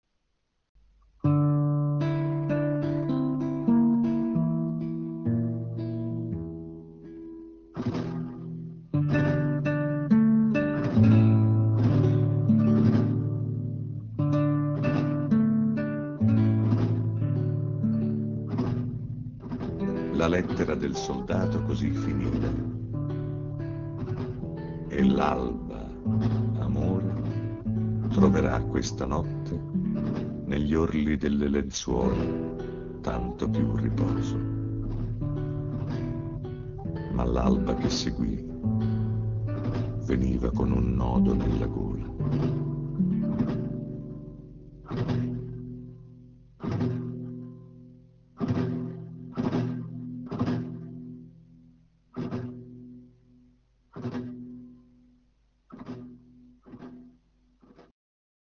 Per voi le più prestigiose interpretazioni del Maestro Arnoldo Foà